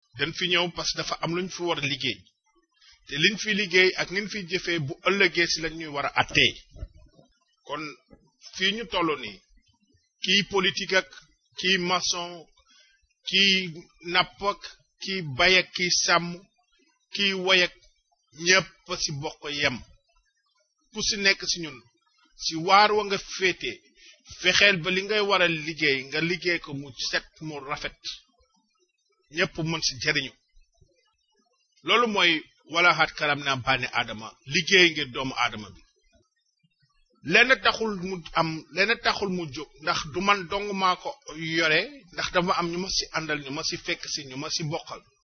Here’s a recording in a mystery language.
It does sound like a religious speech, perhaps a sermon, either from within the muslim communities of the region, or from a Christian gospel outreach ministry.
The language has plenty of nasals….and it also has short syllables.